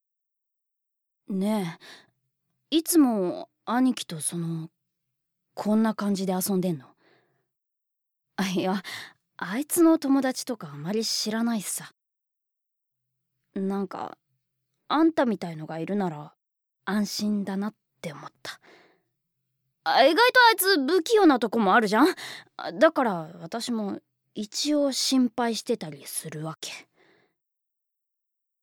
ボイスサンプル
ボイスサンプル３